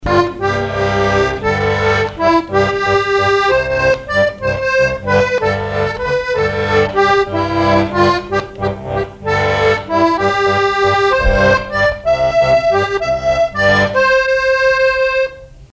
danse : valse
Chansons et répertoire du musicien sur accordéon chromatique
Pièce musicale inédite